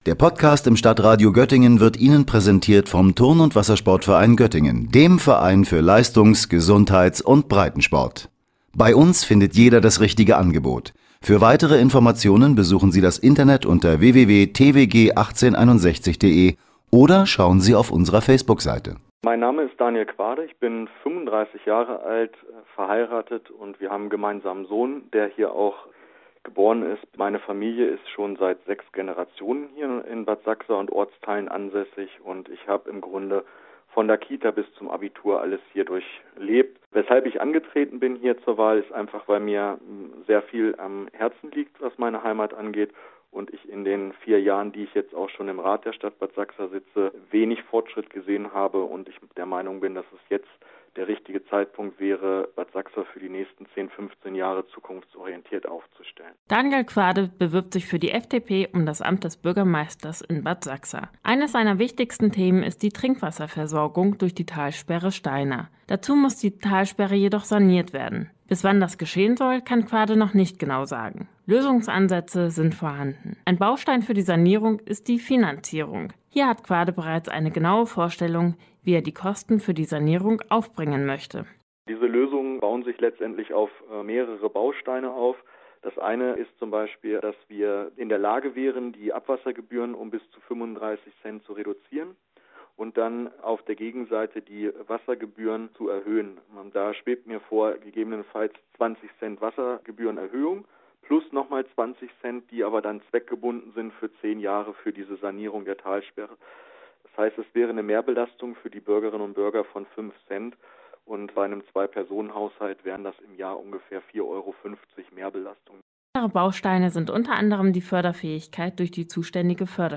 Beiträge > Bürgermeisterwahl Bad Sachsa – Kandidatenporträt